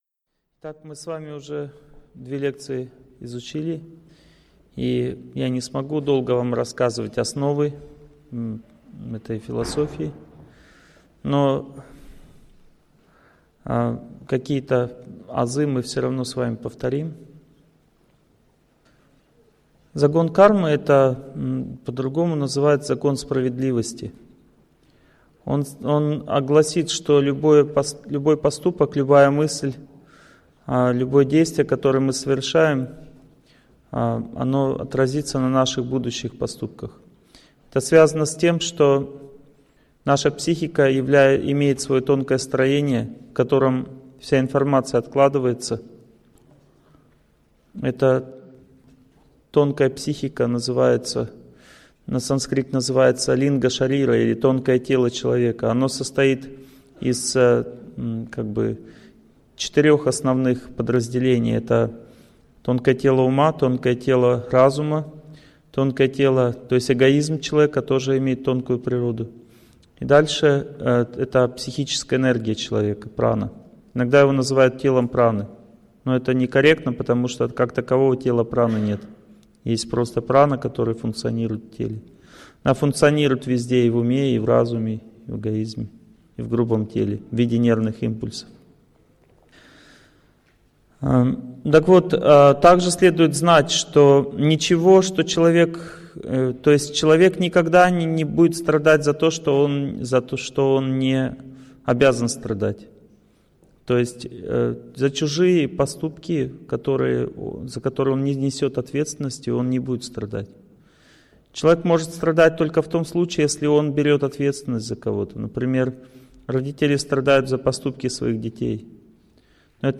Аудиокнига Закон перевоплощения | Библиотека аудиокниг